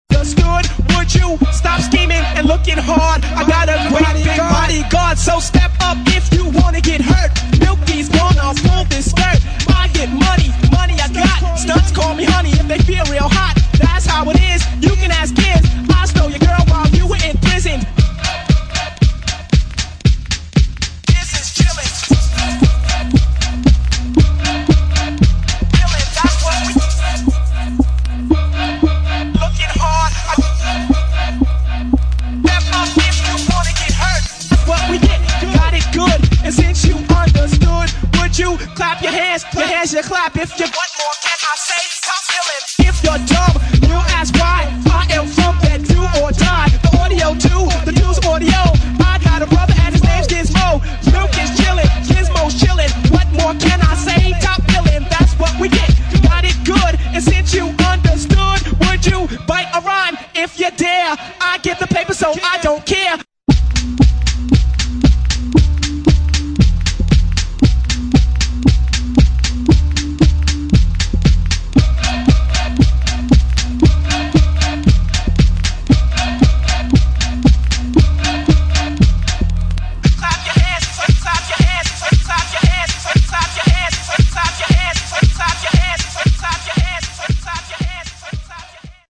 [ HOUSE / DISCO ]